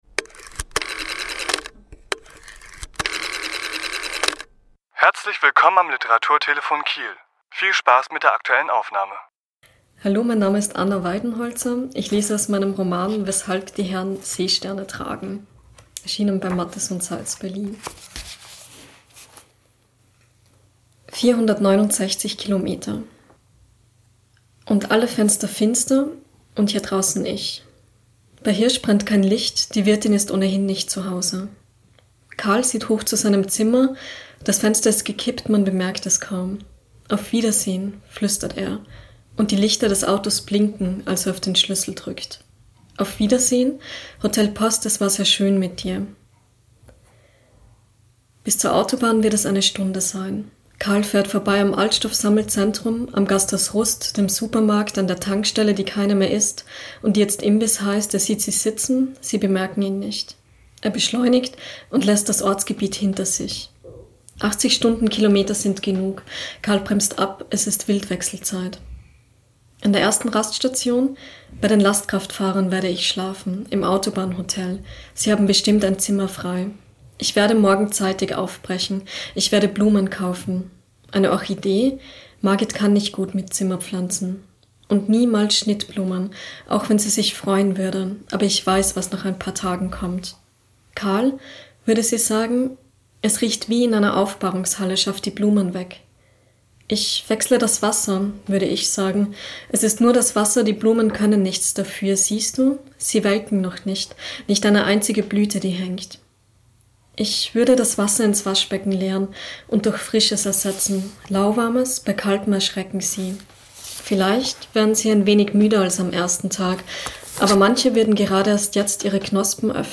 Autor*innen lesen aus ihren Werken
Die Aufnahme entstand bei einer Lesung im Literaturhaus Schleswig-Holstein am 26.4.2017.